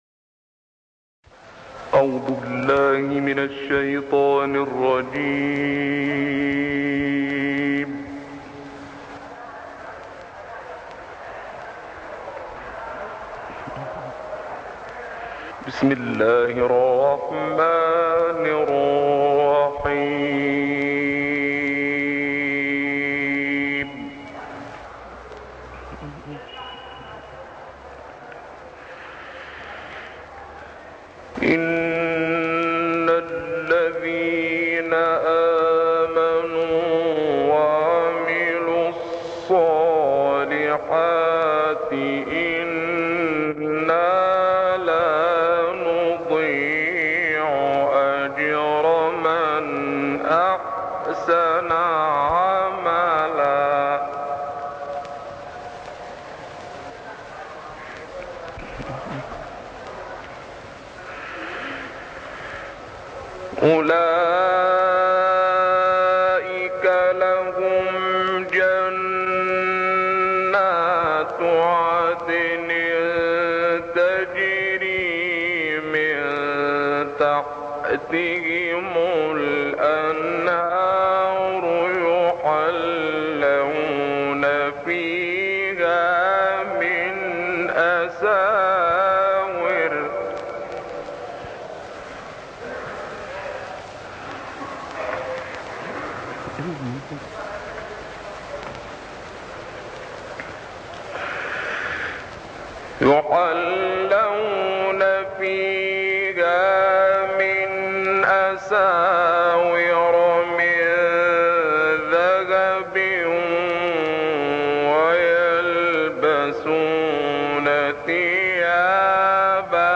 گروه فعالیت‌های قرآنی: قطعه‌ای از تلاوت استاد شعبان عبدالعزیز صیاد از آیات ۳۰ تا ۵۰ سوره کهف ارائه می‌شود.